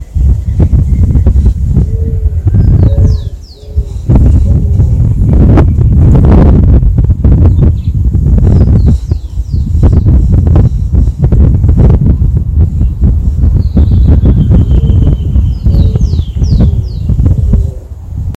Avoante (Zenaida auriculata)
Nome em Inglês: Eared Dove
Localidade ou área protegida: Concordia
Condição: Selvagem
Certeza: Observado, Gravado Vocal
Torcaza-comun_1.mp3